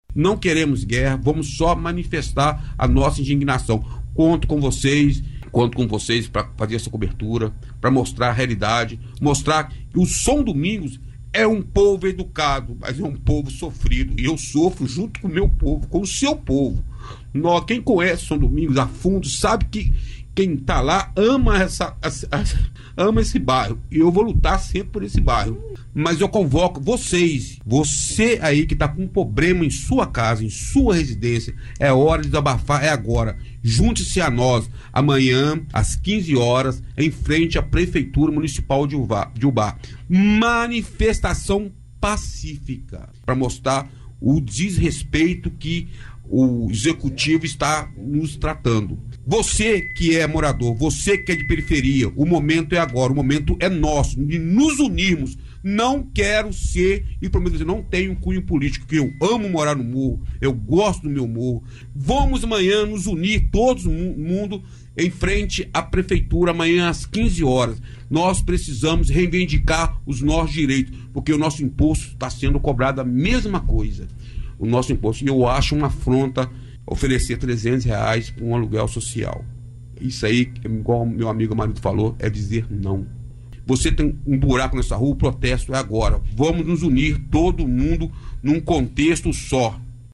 em entrevista à Rádio Ubaense FM